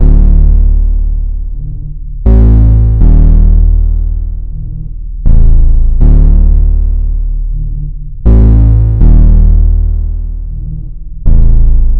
Tag: 80 bpm Cinematic Loops Bass Loops 2.20 MB wav Key : Unknown